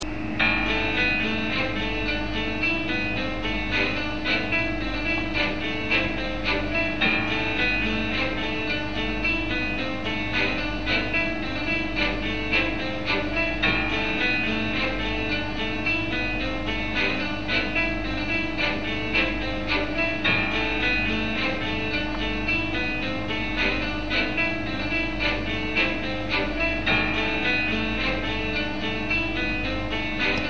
28k 56k 100k _____________ Thème musical